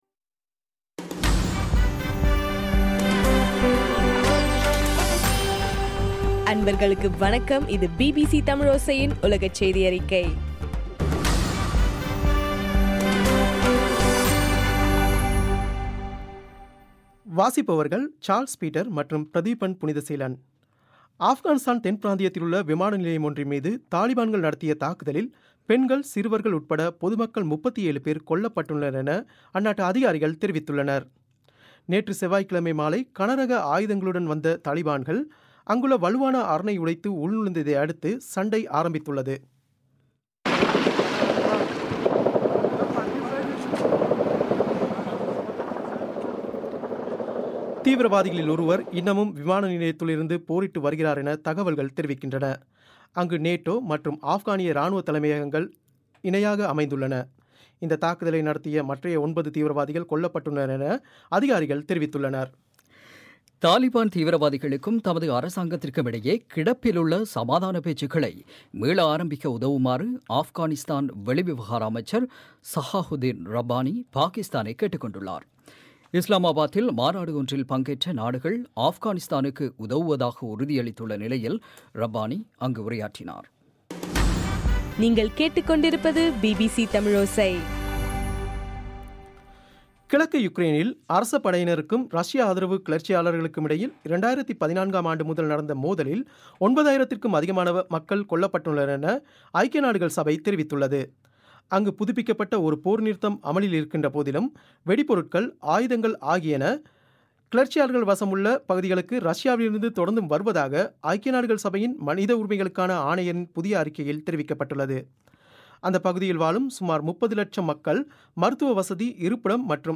டிசம்பர் 9, 2015 பிபிசி தமிழோசையின் உலகச் செய்திகள்